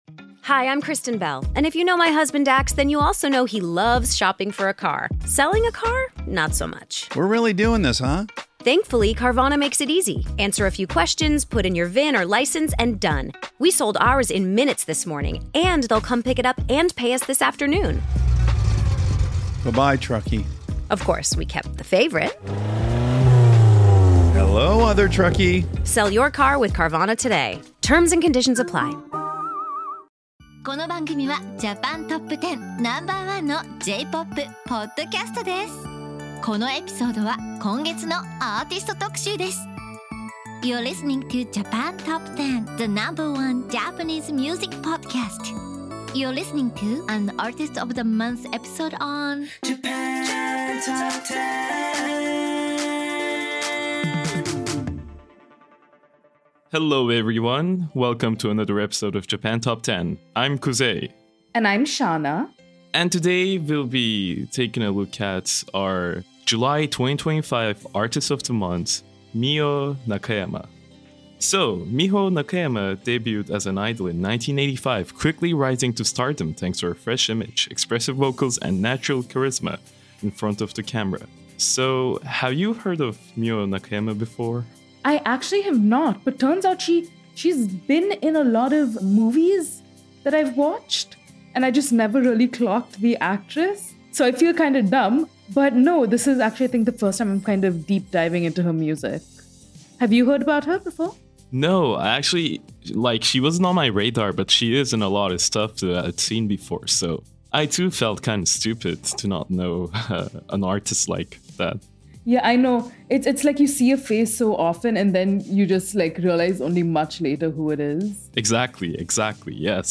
Japan Top 10 is a podcast show that focuses on the discovery of Japanese music culture. Various hosts provide entertaining, engaging and educational commentary that help instill Japanese music cultural knowledge into its listeners.